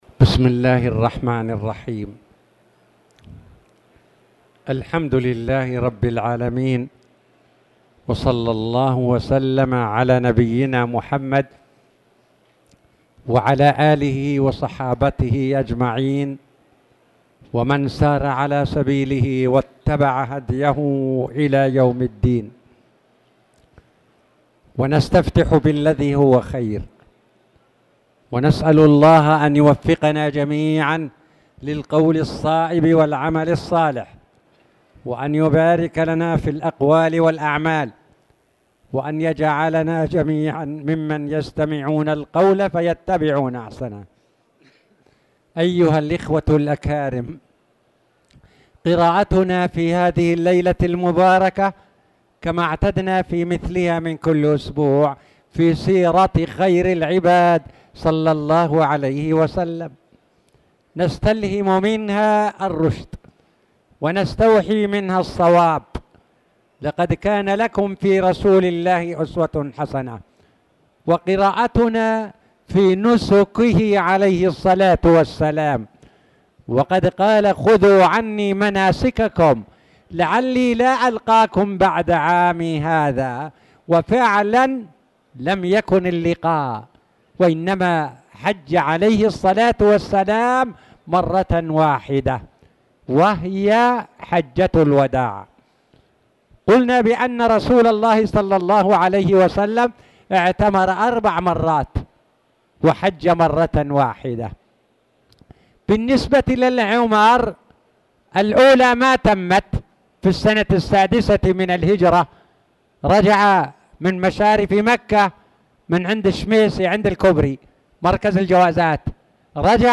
تاريخ النشر ١٩ محرم ١٤٣٨ هـ المكان: المسجد الحرام الشيخ